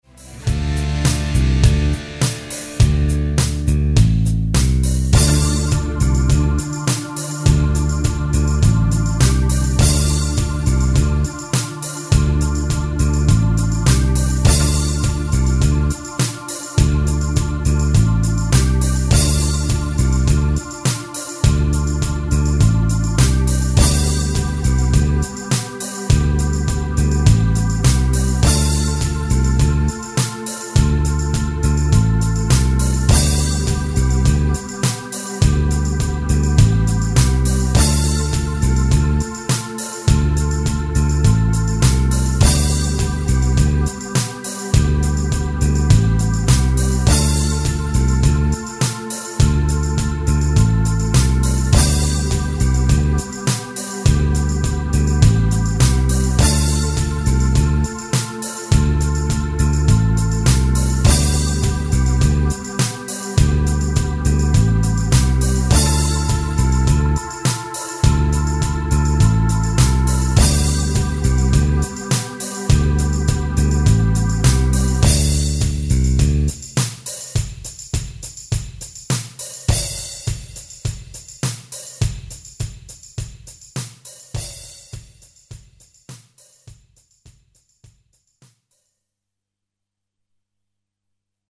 Backing SOLO